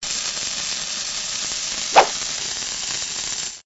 TL_dynamite.ogg